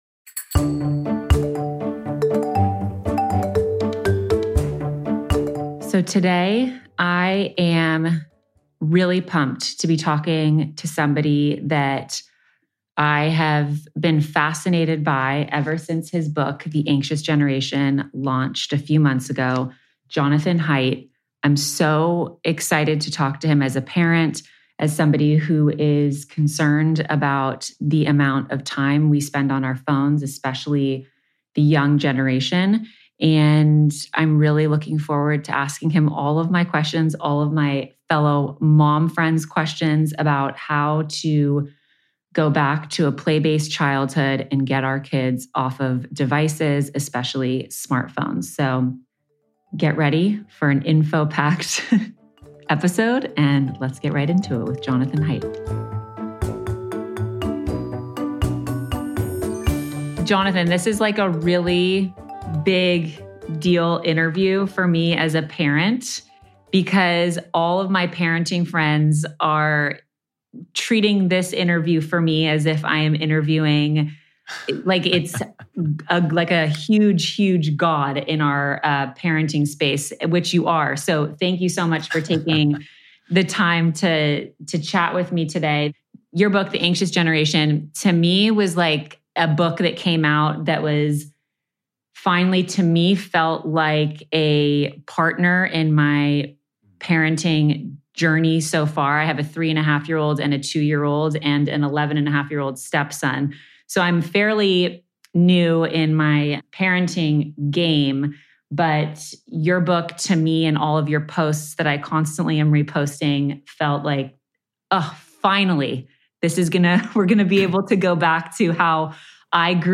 In this interview, we explore the impact of screen time and social media on younger generations and the importance of returning to a play-based childhood through clear boundaries with technology. Jonathan offers straightforward advice for parents on how to implement effective boundaries to delay and monitor screen time.